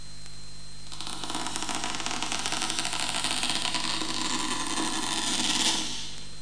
coffin.mp3